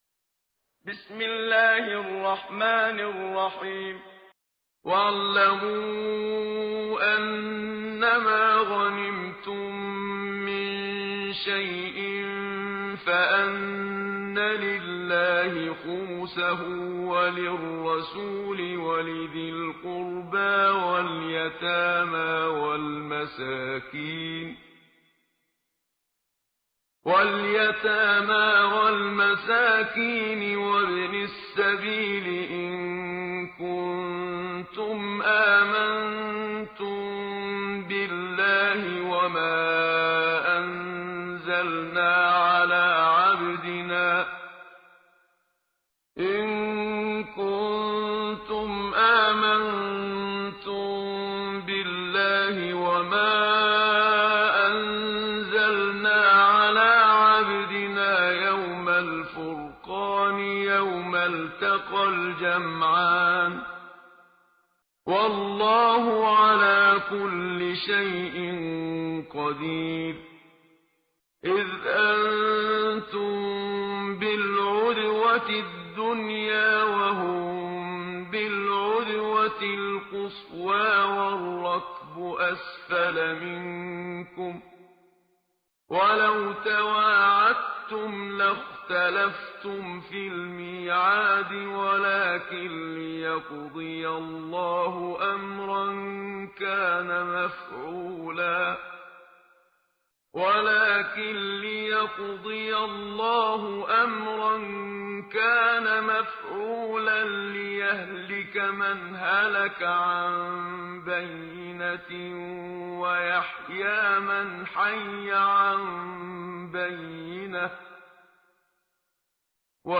ترتیل جزء دهم قرآن با صدای استاد منشاوی
تهران- الکوثر: در دهمین روز ماه مبارک رمضان، تلاوت جزء دهم قرآن کریم را با صدای قاری مشهور مصری استاد محمد صدیق منشاوی، با هم می شنویم.